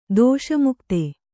♪ dōṣa mukte